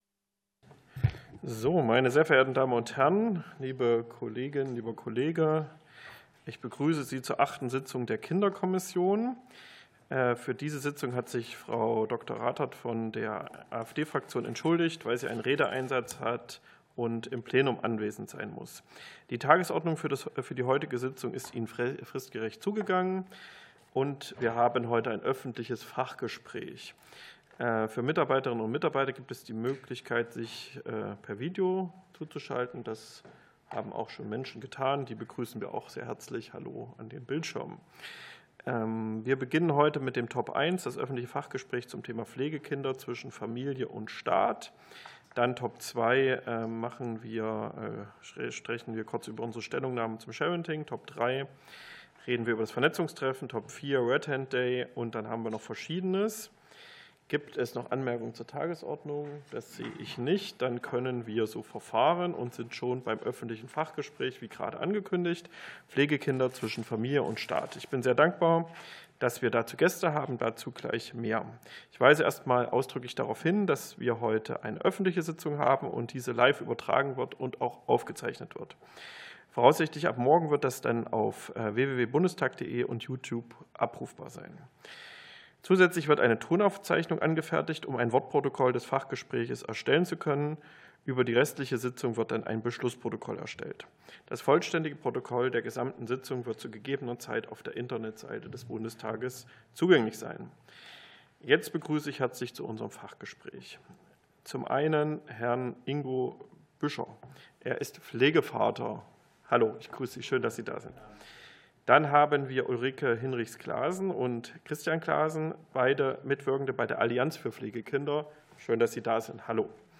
Fachgespräch der Kinderkommission